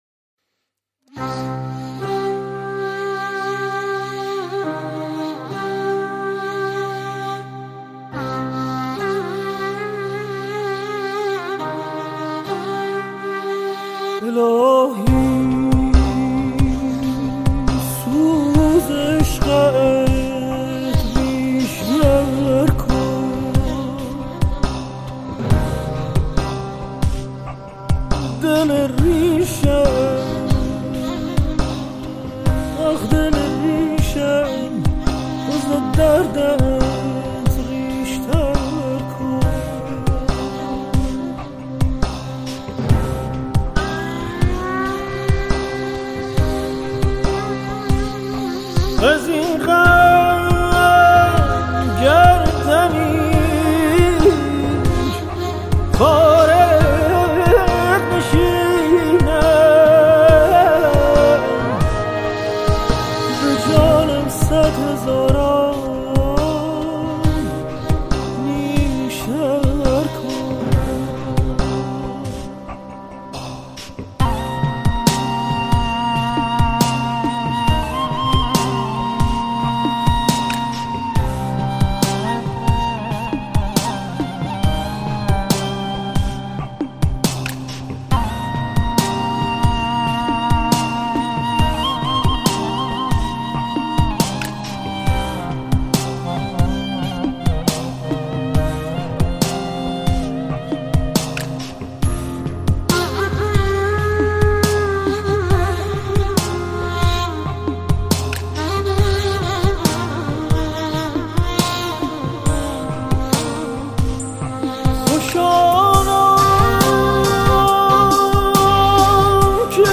تلفیقی از موسیقی سنتی و مدرن منتشر شد.